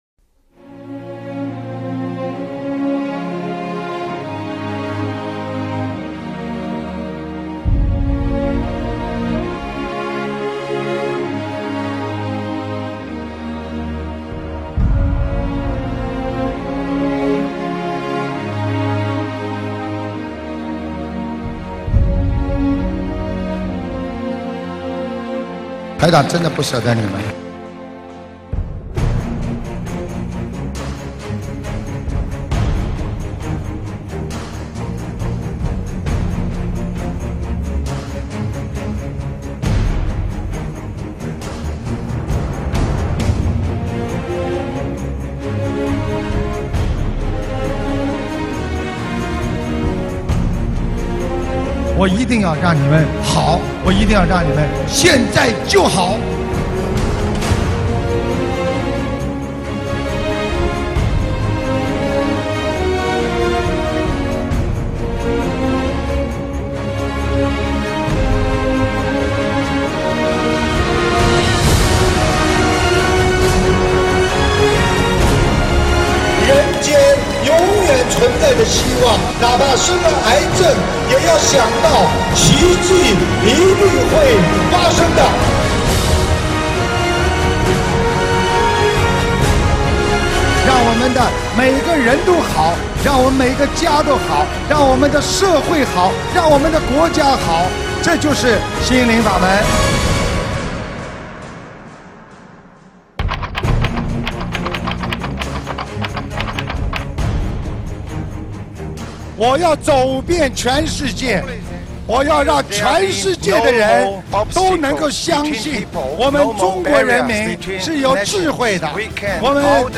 目录：☞ 法会开示